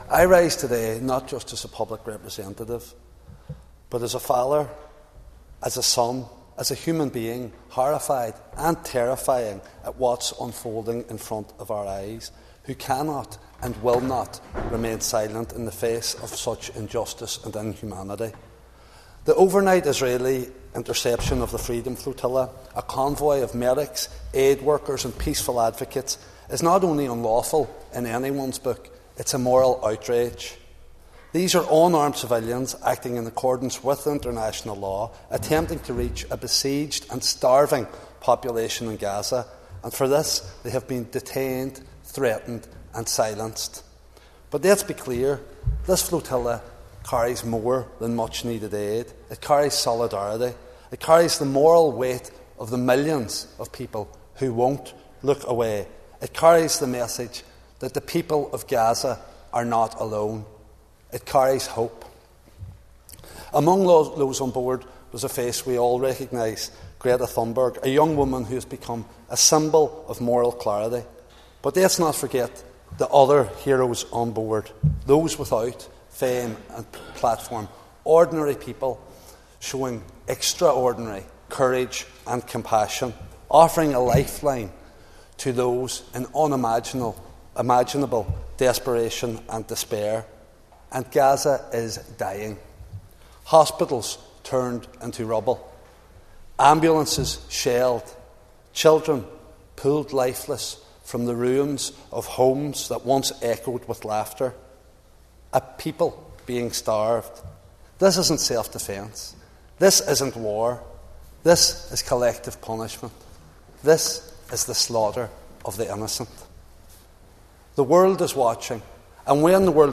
In the Assembly earlier today, Foyle MLA Mark H Durkan urged the Executive to speak out against the Israeli actions.